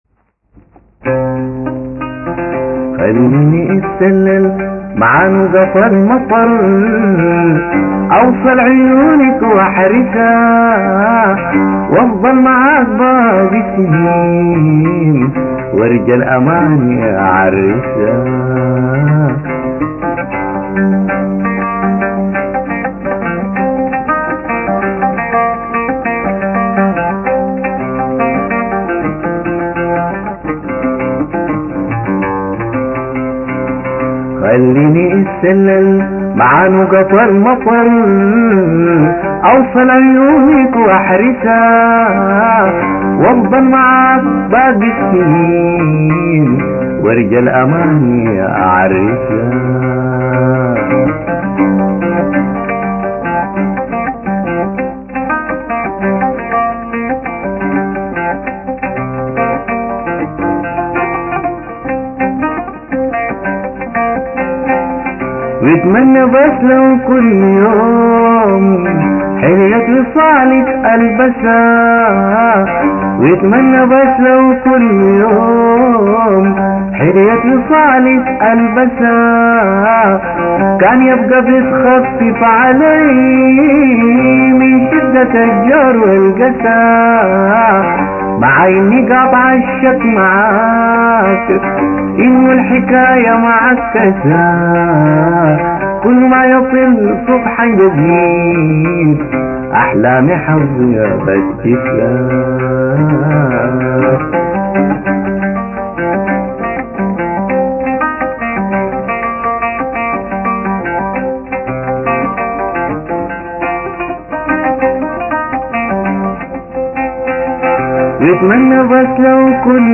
تجربة بالعود
حلم-الهوي-بالعود.mp3